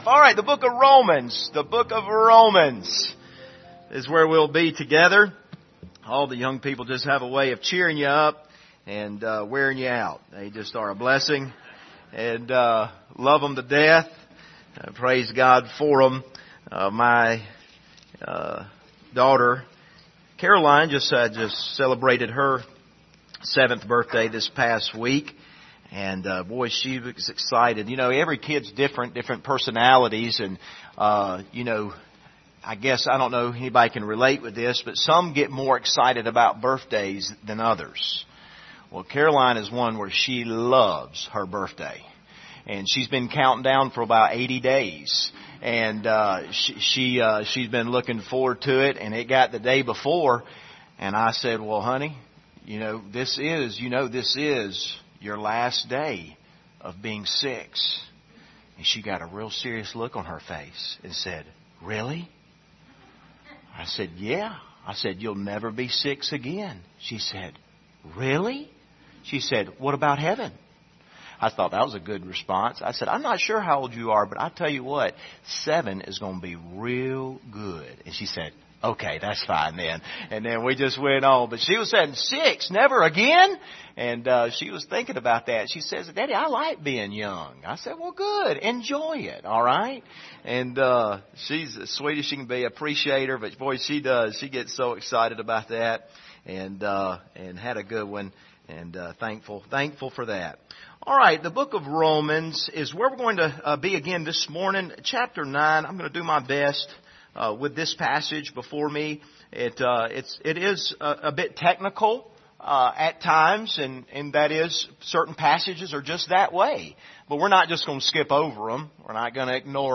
Passage: Romans 9:6-13 Service Type: Sunday Morning